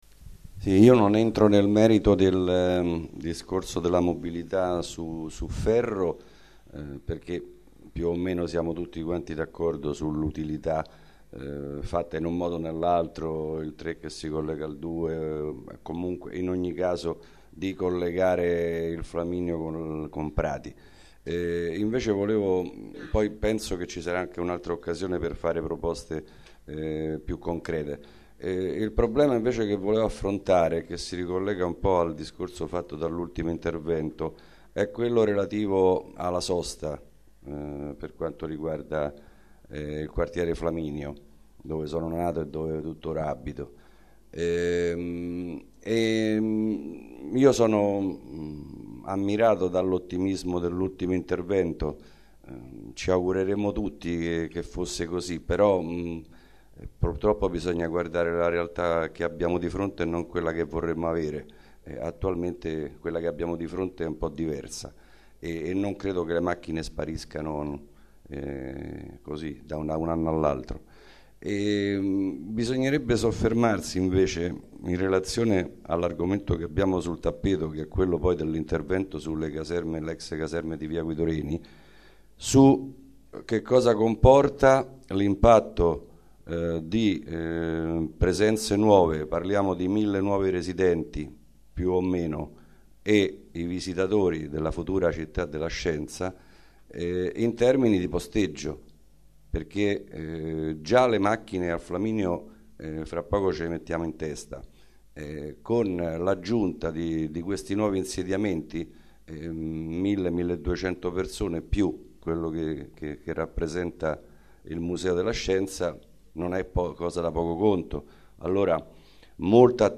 Registrazione integrale dell'incontro svoltosi il 17 aprile 2014 nell'Aula F1 della Facoltà di Architettura in Via Flaminia, 70